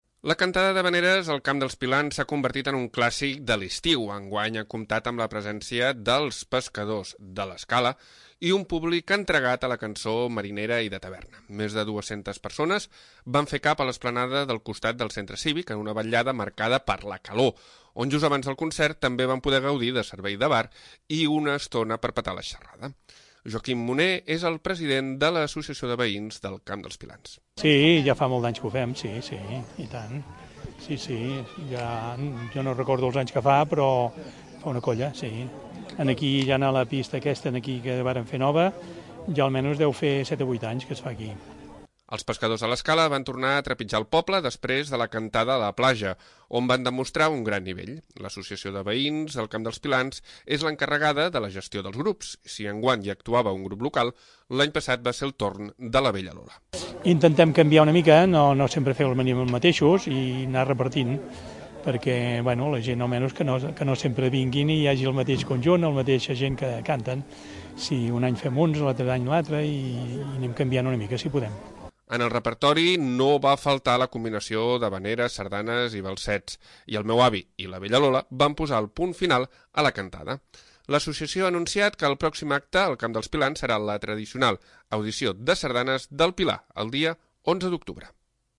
Els Pescadors de l'Escala han actuat a la cantada d'havaneres d'estiu del Camp dels Pilans. Més de dues-centes persones s'han atansat al voltant de l'esplanada del centre cívic en una nit marcada per la calor i la música marinera.
En el repertori no va faltar la combinació d'havaneres, sardanes i valsets i El Meu Avi  i La Bella Lola van posar el punt final a la cantada.